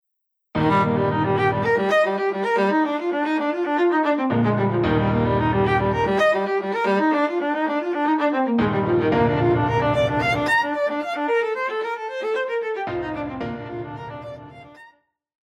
Classical
Viola
Piano
Solo with accompaniment